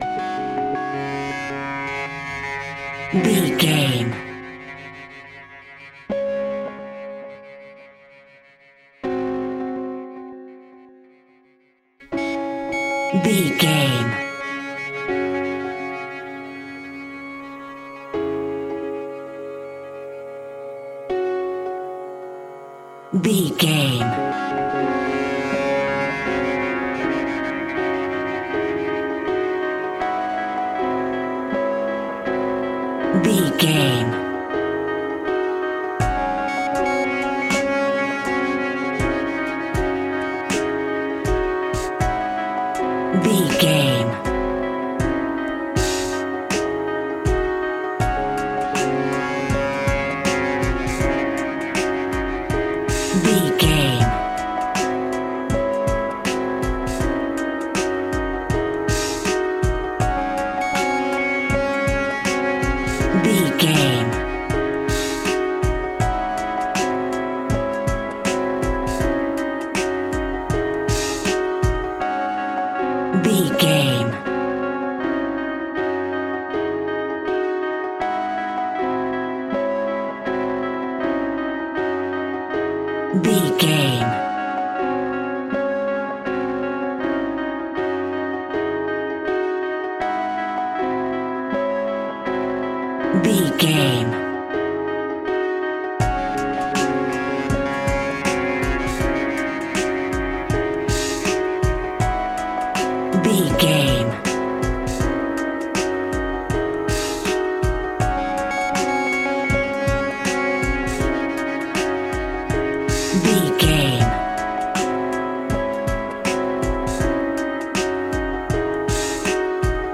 Aeolian/Minor
C#
tension
ominous
eerie
synthesiser
drums
Horror synth
Horror Ambience
electronics